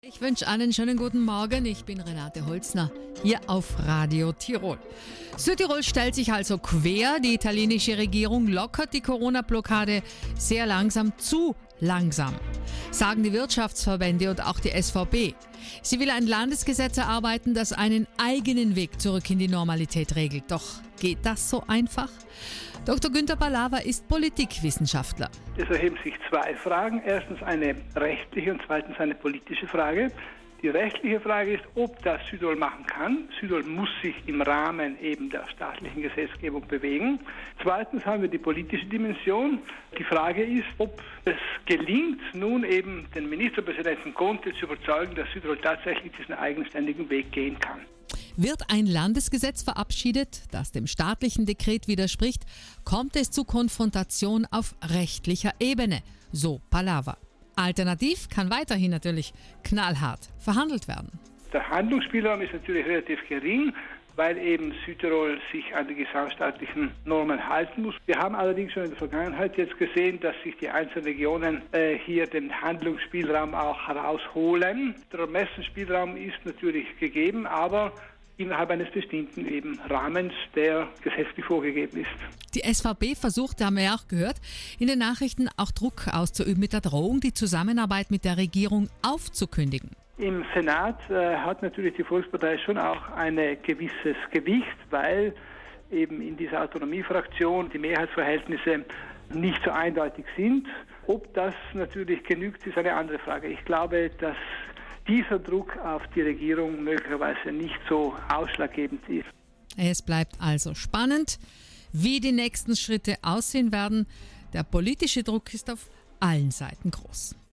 Politikwissenschaftler